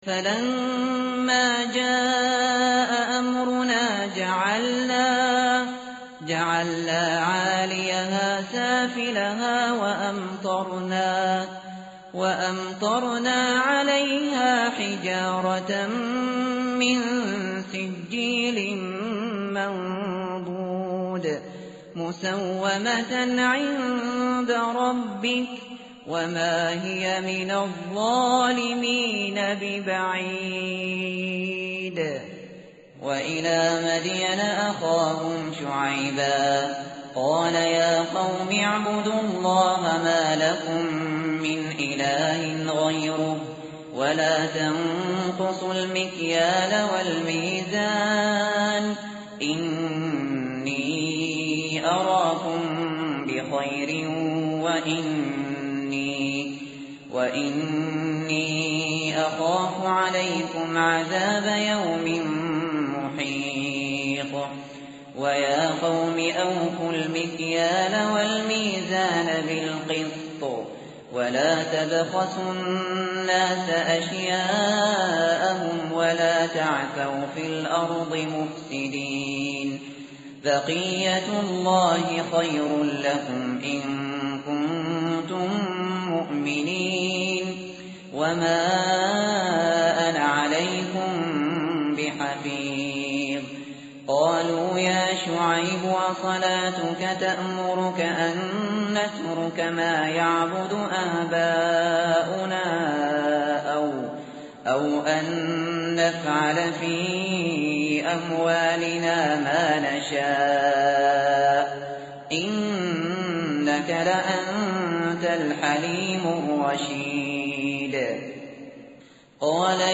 متن قرآن همراه باتلاوت قرآن و ترجمه
tartil_shateri_page_231.mp3